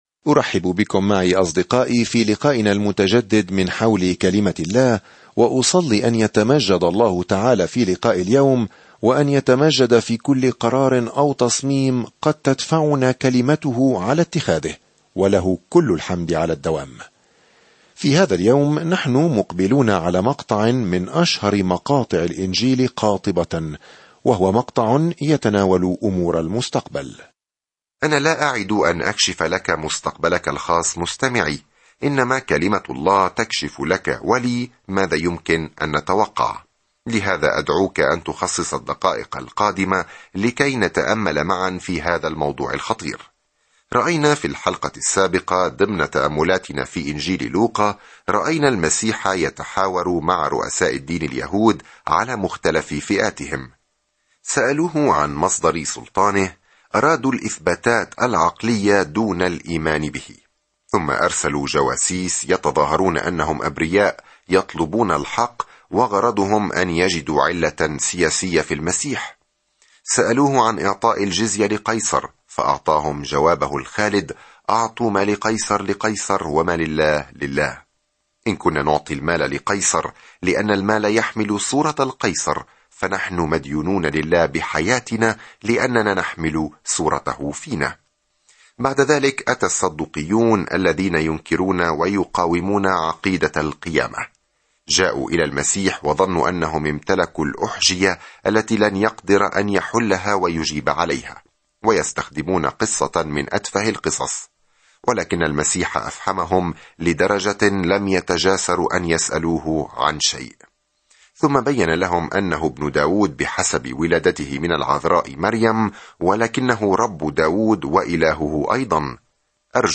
الدراسة الصوتية